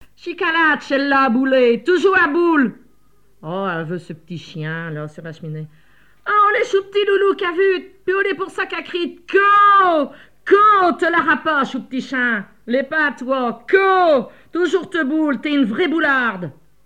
légendes locales, expressions en patois et chansons
Catégorie Locution